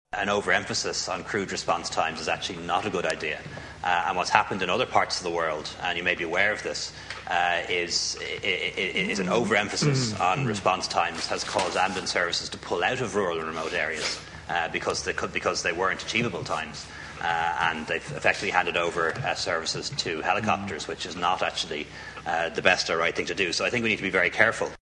Leo Varadkar’s told the Dáil he is committed to improvements, but not necessarily in getting ambulances to people in eight minutes: